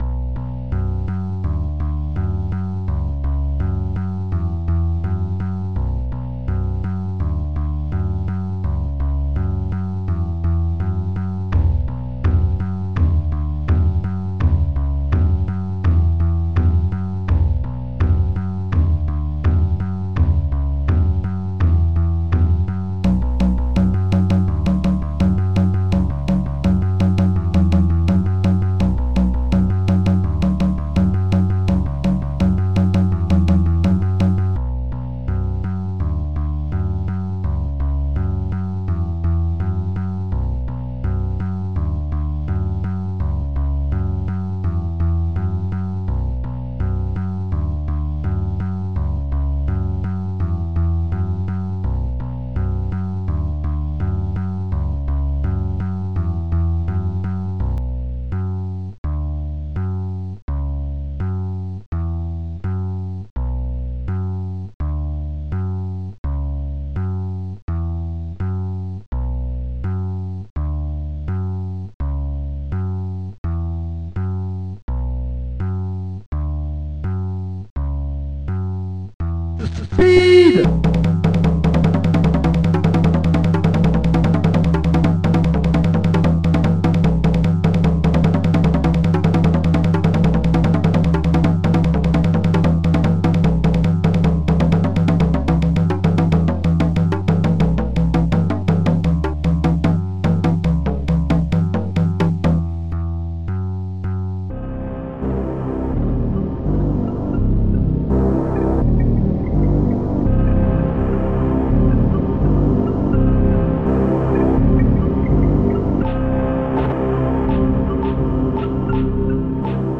Bass
Bongo
Flute de pan
Water